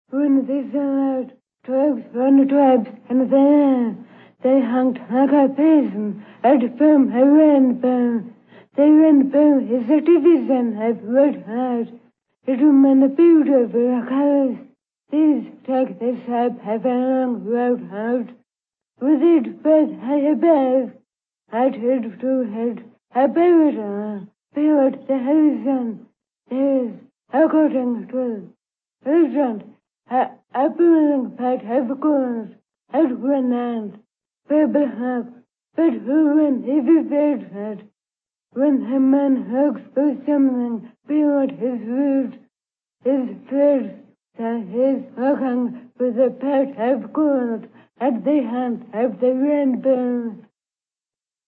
The speakers voice is rated on a five-point scale indicating the degree of breathiness/weakness.
Level 3Moderate breathiness. This sample of breathy voice quality is rated three, moderately breathy. The faulty pattern in fold vibration and/or the lack of tonicity in the folds reduces the intensity of the glottal tone.
These factors seriously disturb the prosodic features of speech and tend to reduce intelligibility.
(Female)